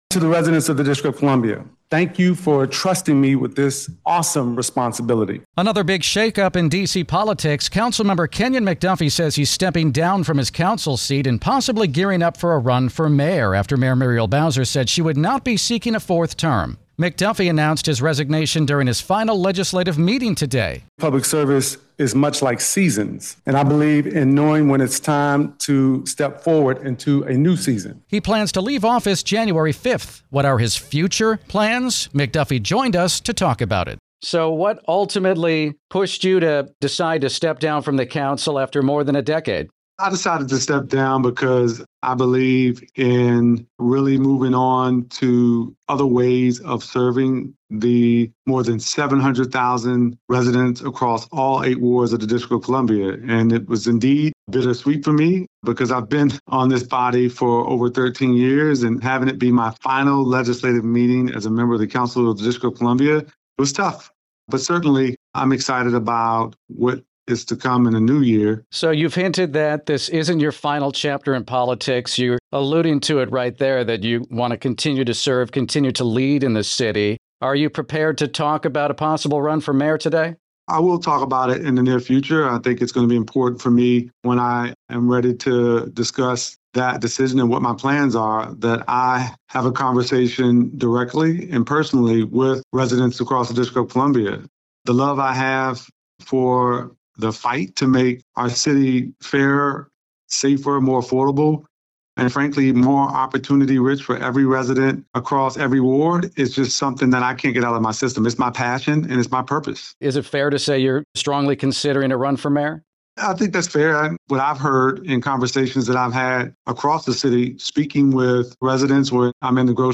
speaks with D.C. Council member Kenyan McDuffie about his resignation and potential mayoral run